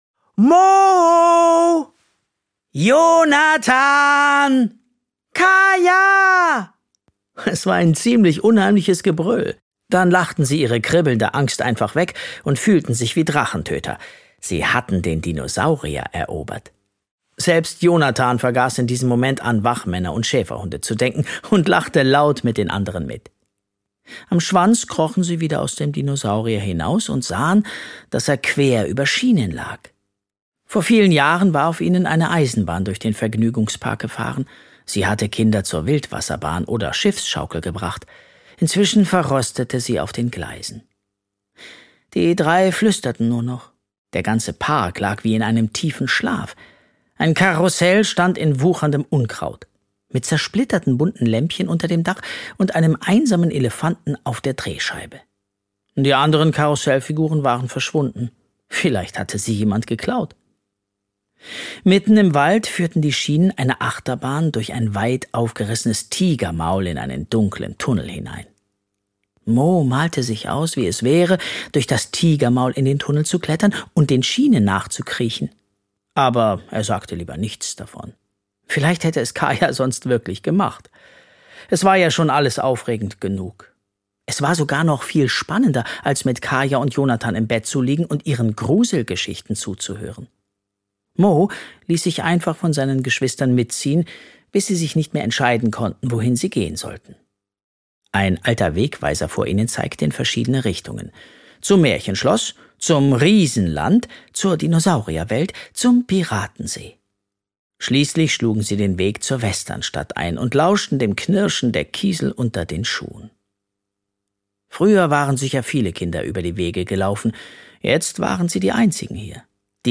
Wenn der geheime Park erwacht, nehmt euch vor Schabalu in Acht - Oliver Scherz - Hörbuch